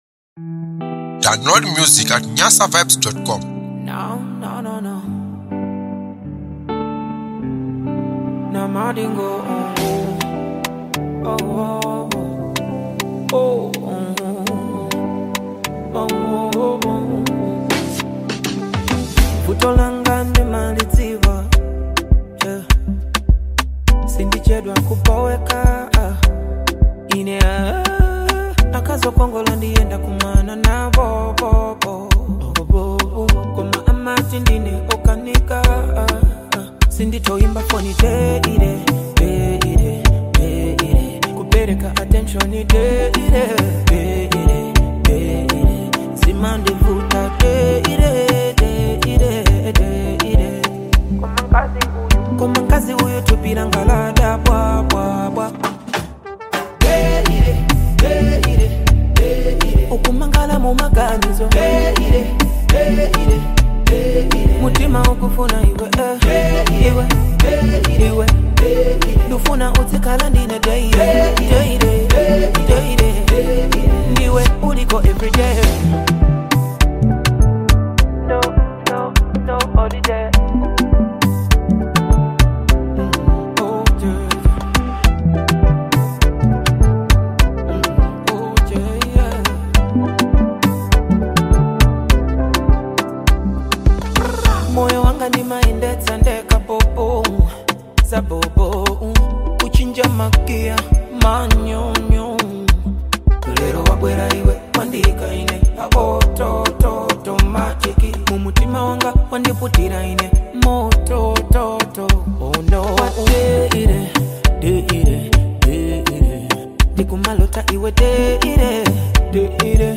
Afro song